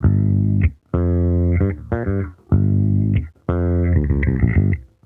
Index of /musicradar/sampled-funk-soul-samples/95bpm/Bass
SSF_JBassProc2_95G.wav